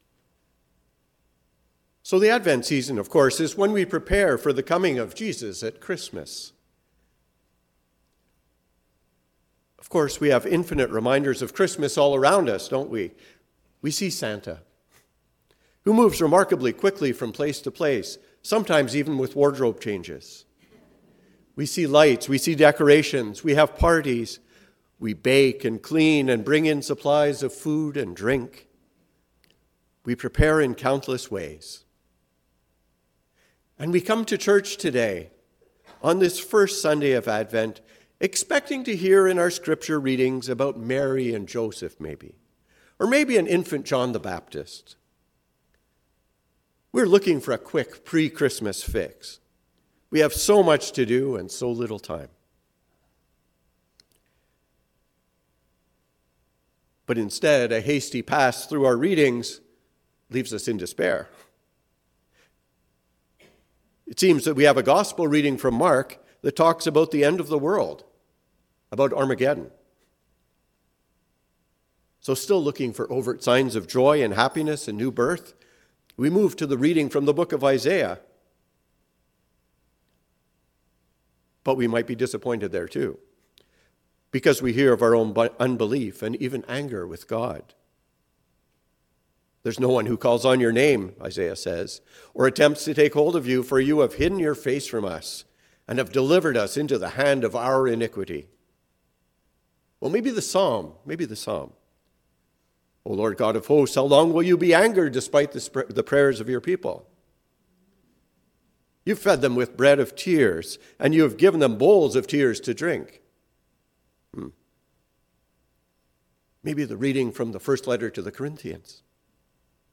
Unsettled by Advent. A sermon for the First Sunday of Advent